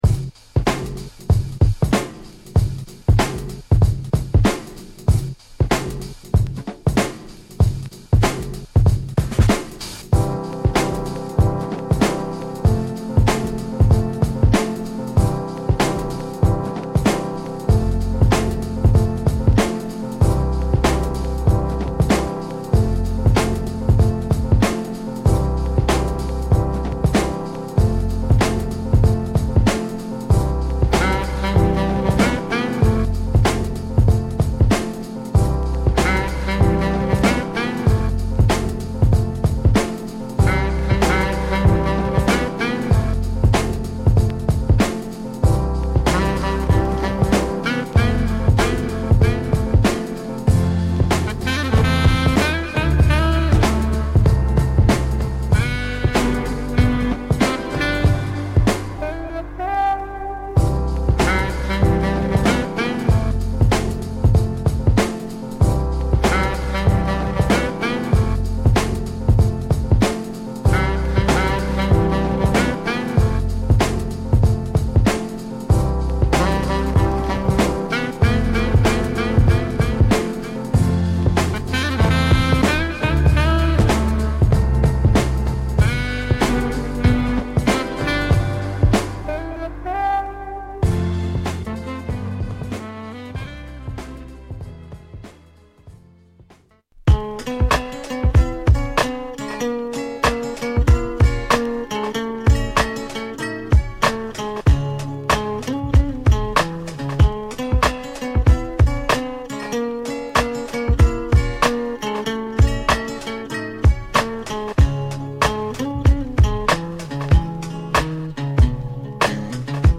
ラグドで太いドラムブレイクに、リードを取るサックスもナイスですね。